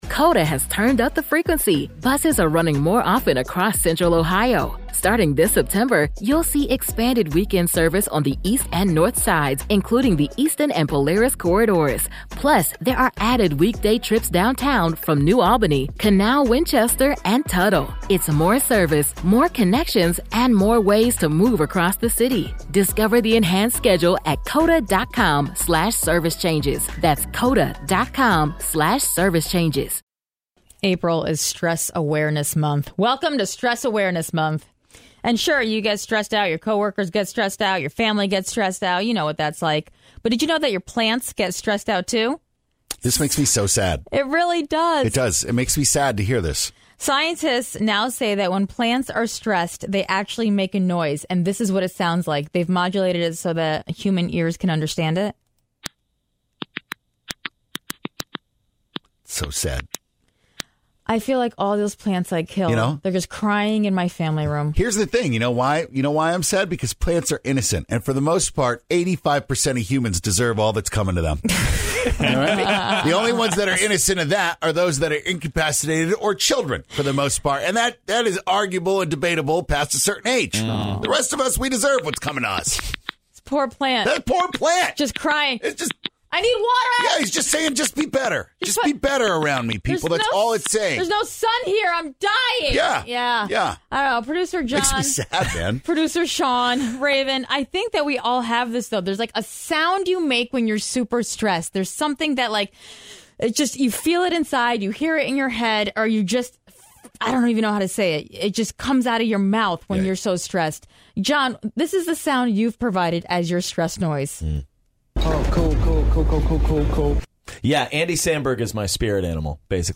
April is Stress Awareness Month, and scientists have discovered that plants actually make a sound when they are feeling stressed! Listen to it in the podcast.